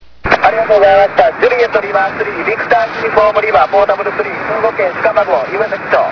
ちなみに移動地は清水市駒越海岸。
SP　SAMPLE-1　DPでの受信　(REAL AUDIO)
SAMPLE-1では５エレの方が若干強く聞こえます。しかし、ＤＰでも十分に強く聞こえます。